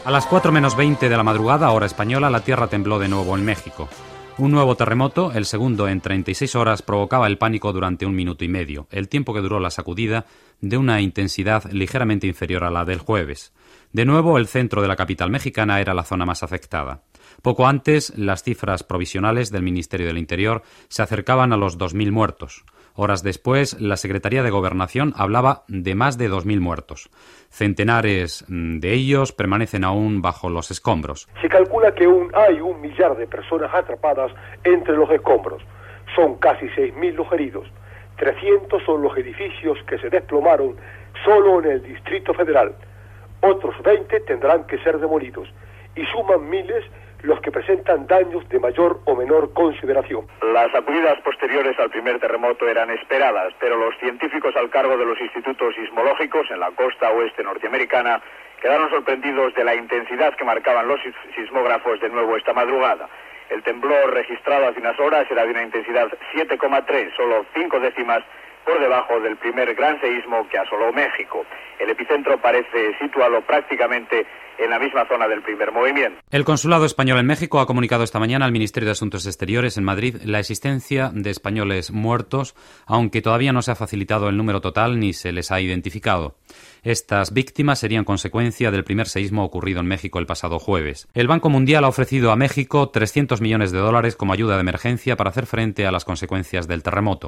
Informació dels dos terratrèmols de ciutat de Mèxic ,dels dies 19 i 21 de setembre de 1985, amb connexió amb els corresponsals de RNE a Costa Rica i els EE.UU.
Informatiu